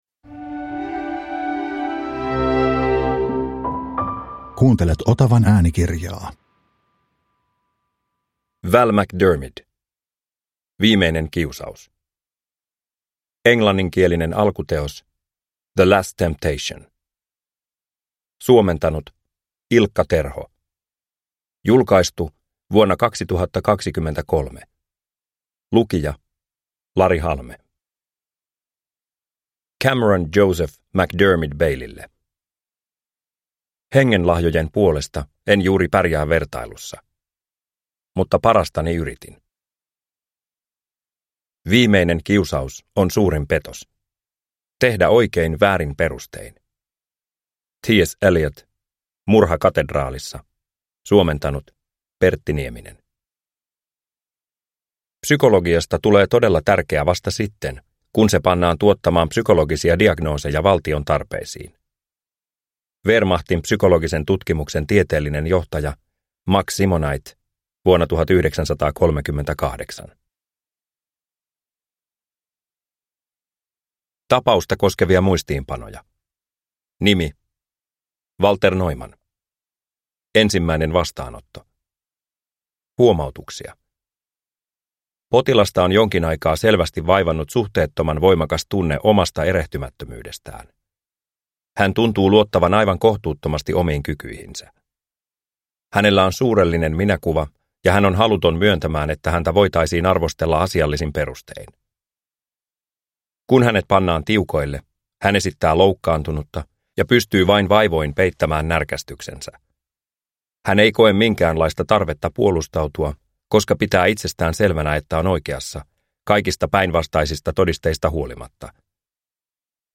Viimeinen kiusaus – Ljudbok – Laddas ner